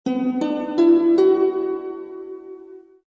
Descarga de Sonidos mp3 Gratis: romantico.